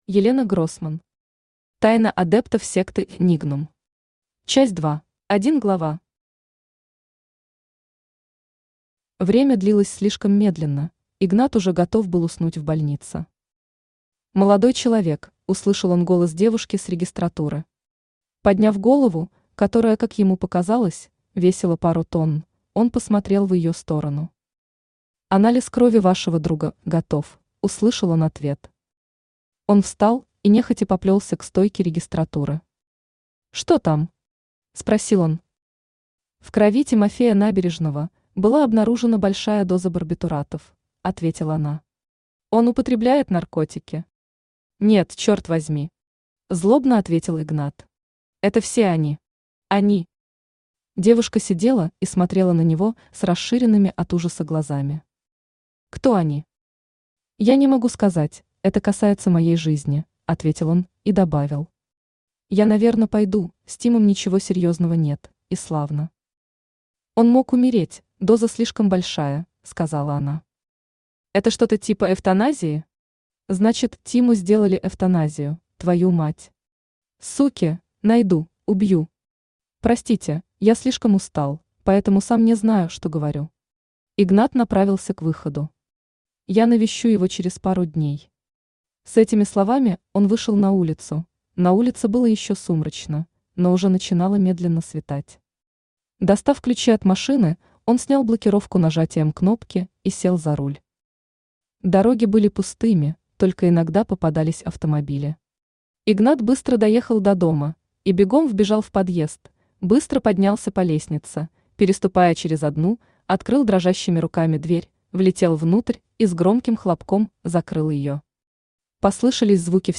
Аудиокнига Тайна адептов секты «Нигнум». Часть 2 | Библиотека аудиокниг
Часть 2 Автор Елена Гроссман Читает аудиокнигу Авточтец ЛитРес.